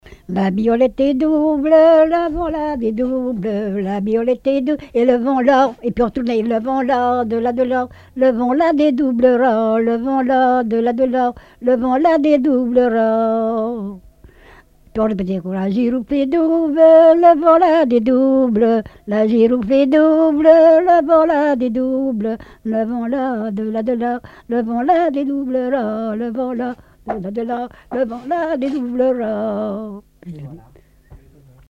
Couplets à danser
collecte en Vendée
Répertoire de chants brefs et traditionnels